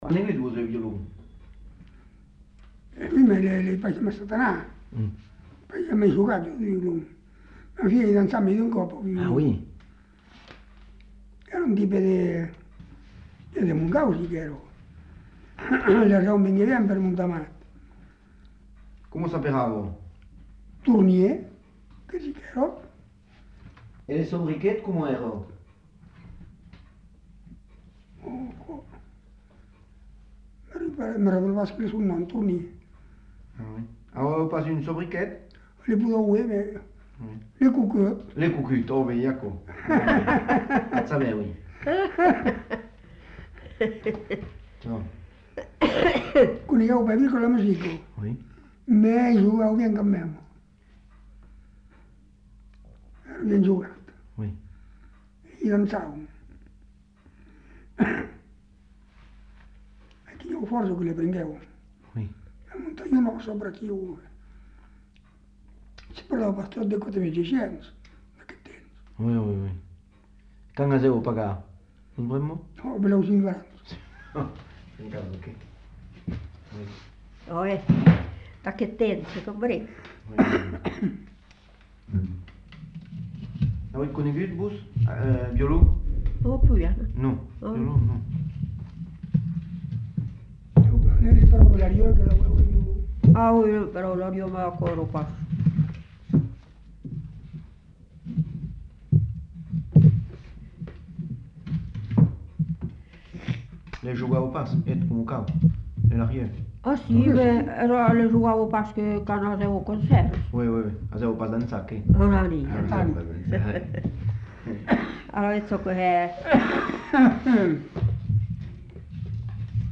Lieu : Montadet
Genre : témoignage thématique